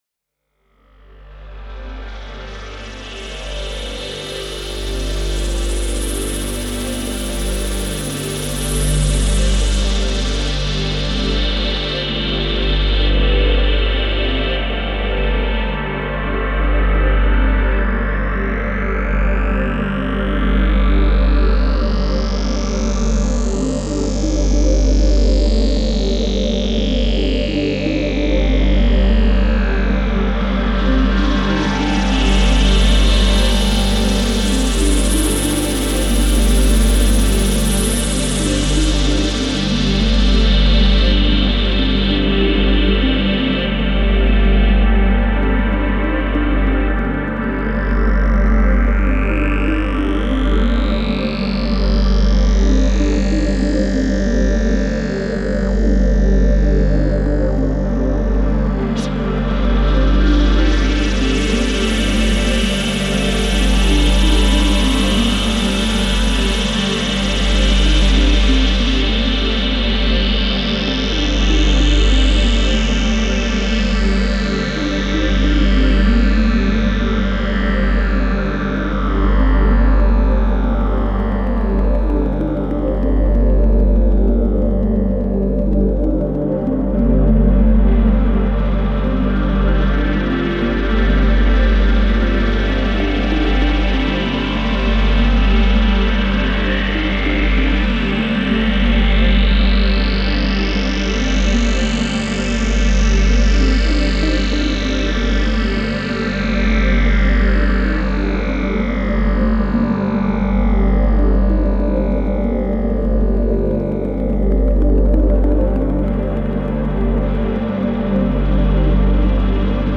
Muzica pentru comuniunea cu Megaatributul Dumnezeiesc al lui Paramatman – Absolutul Ultim Dumnezeiesc (30 min):